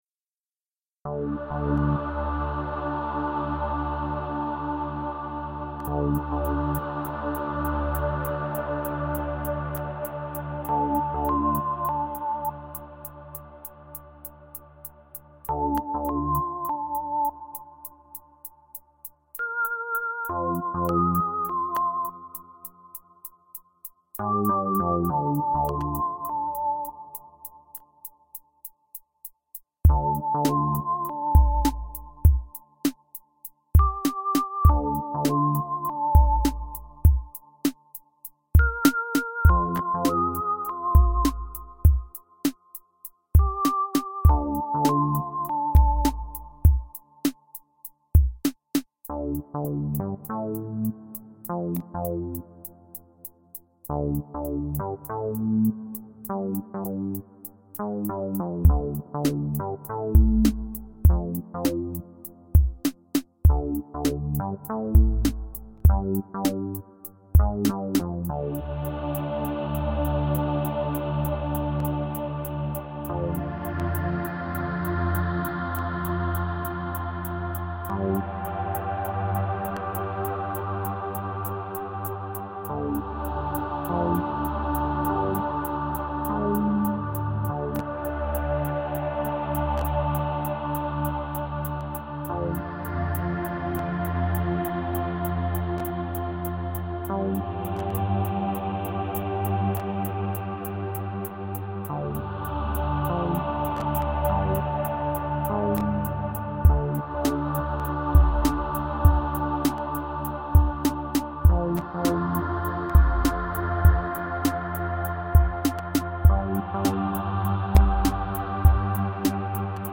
Välineet: zynaddsubfx, rosegarden, jack audio connection kit, hydrogen. Hydrogen tapansa mukaan hieman temppuili tallennusvaiheessa.